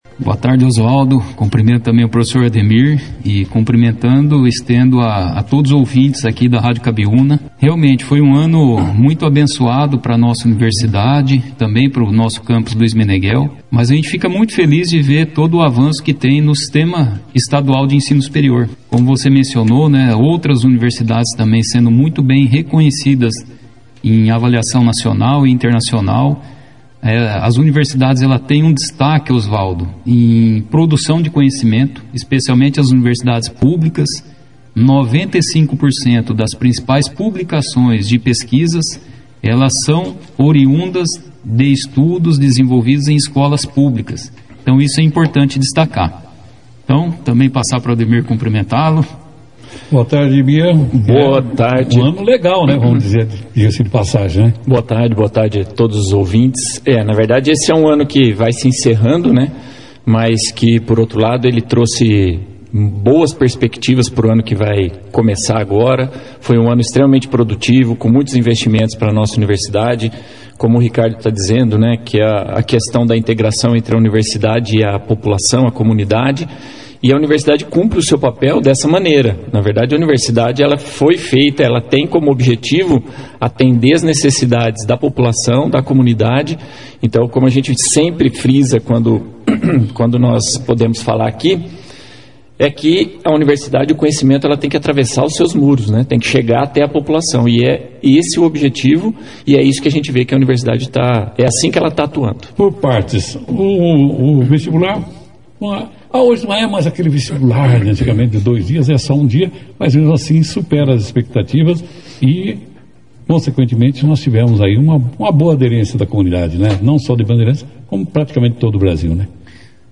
participaram de uma entrevista nesta segunda-feira no Jornal Operação Cidade